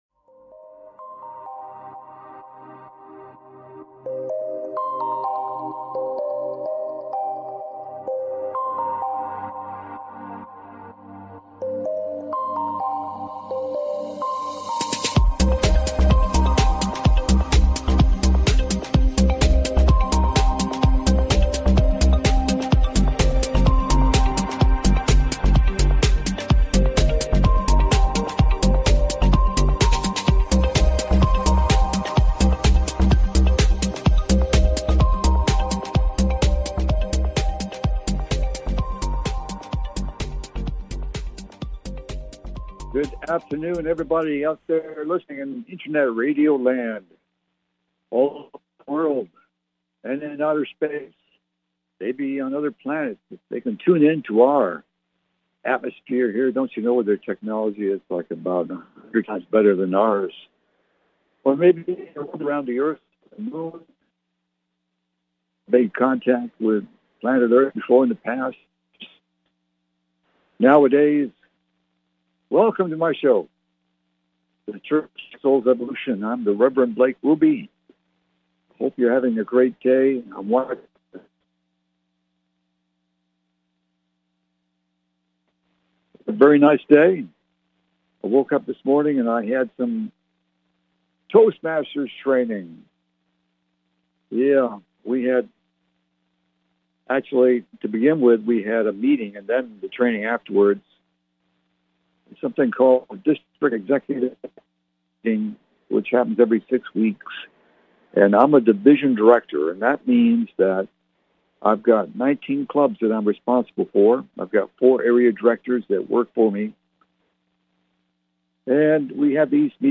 Universe of the Souls Evolution Talk Show